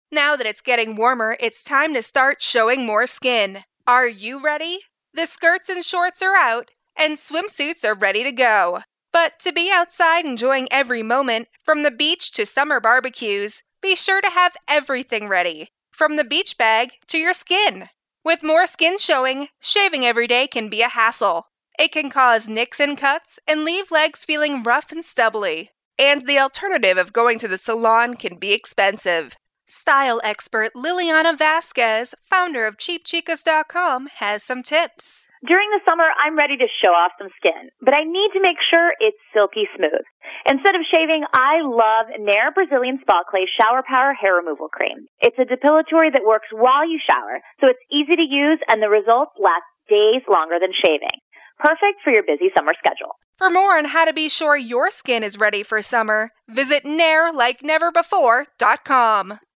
June 29, 2012Posted in: Audio News Release